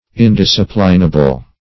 Search Result for " indisciplinable" : The Collaborative International Dictionary of English v.0.48: Indisciplinable \In*dis"ci*plin*a*ble\, a. [Pref. in- not + disciplinable: cf. F. indisciplinable.]
indisciplinable.mp3